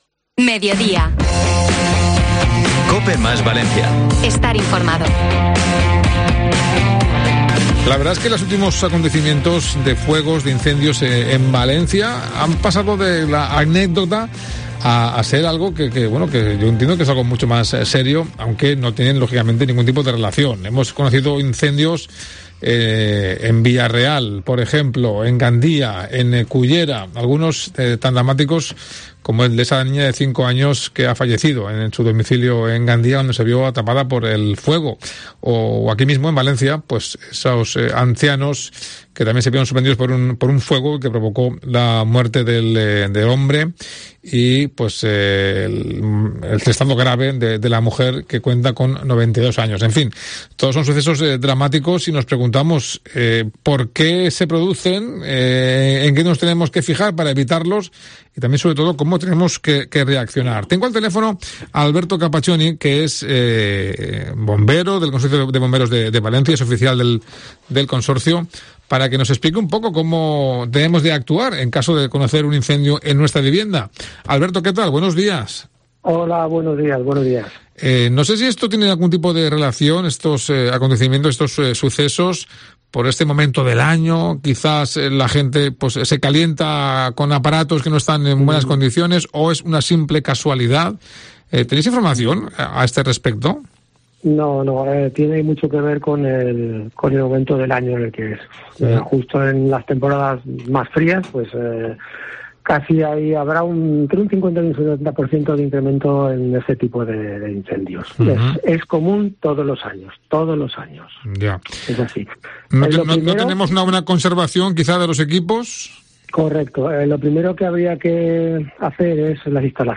El portavoz de bomberos es consciente que revisar cada cierto tiempo toda la instalación o instalaciones de nuestra casa es complicado y que hasta que no se da una desgracia no somos conscientes de la importancia que tiene realizarlo.